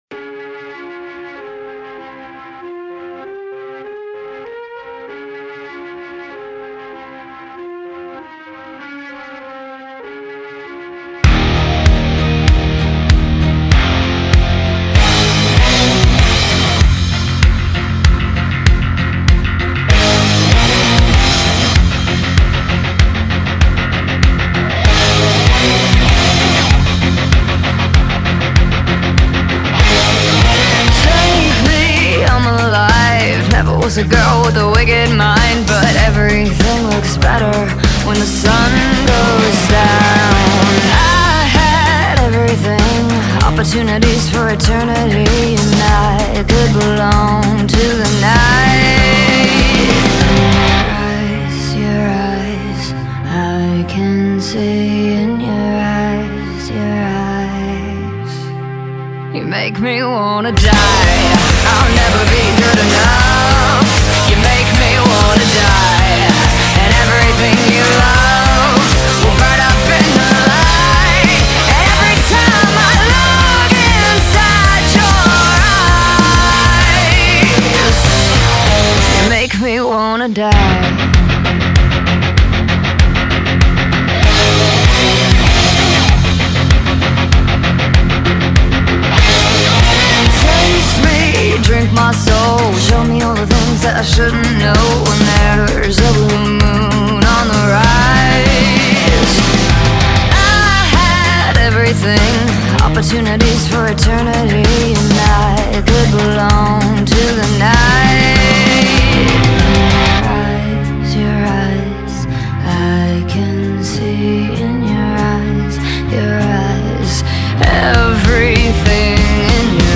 Rock [96]